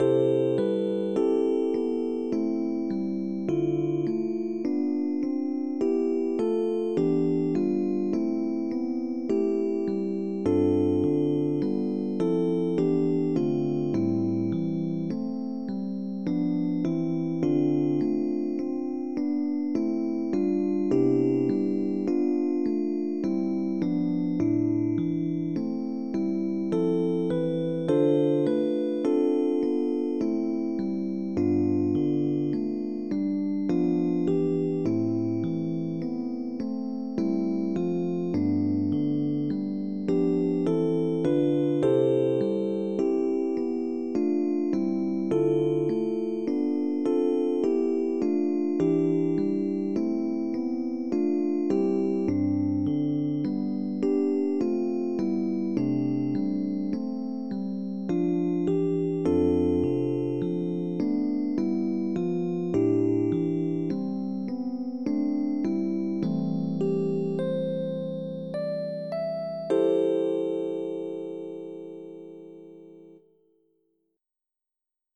Primary Children/Primary Solo
Christmas carol